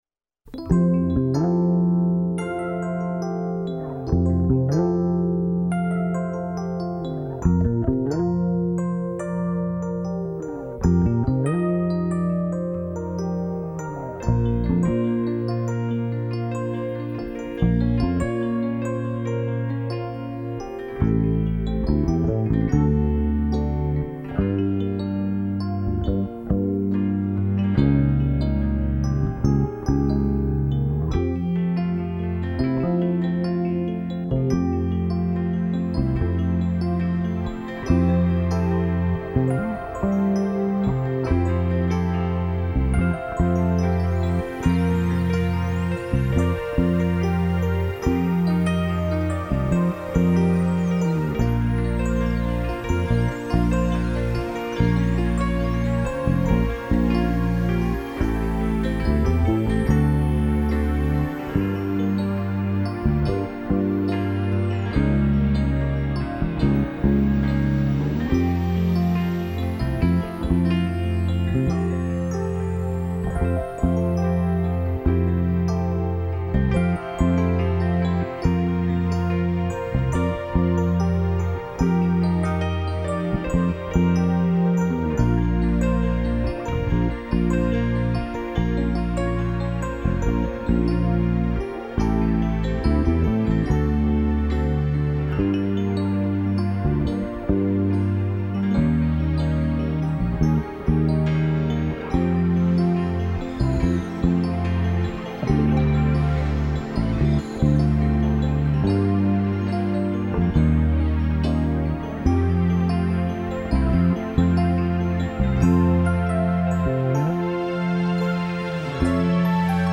piano/keyboard